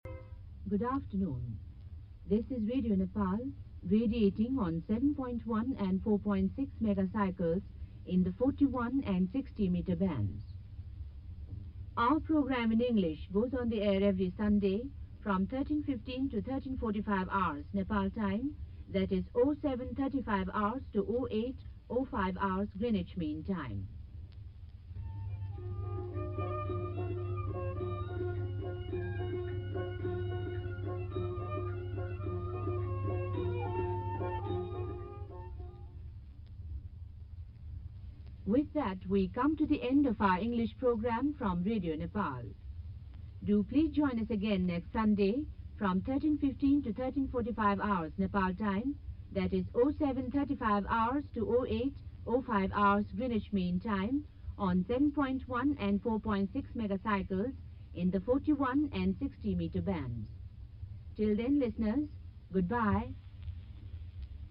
SWL Shortwave Listeners QSL Card Museum
Station ID Audio